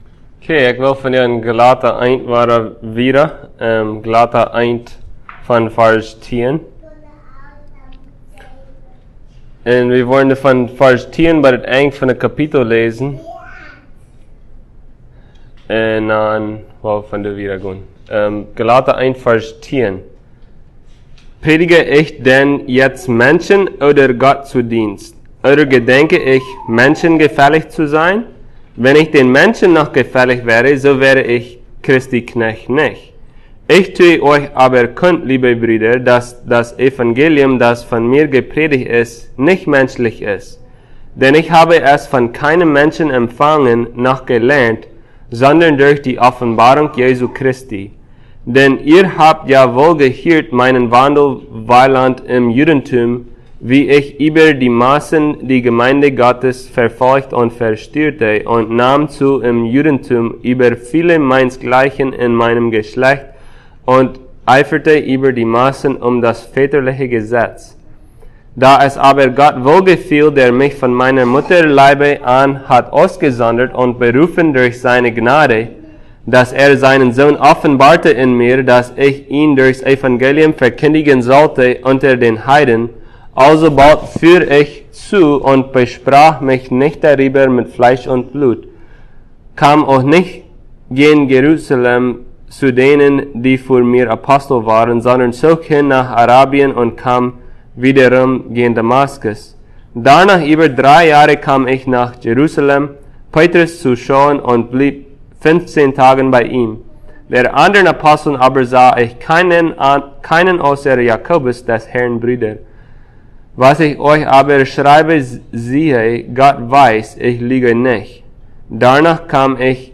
Passage: Galatians 1:10-24 Service Type: Sunday Plautdietsch